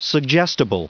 Prononciation du mot suggestible en anglais (fichier audio)
Prononciation du mot : suggestible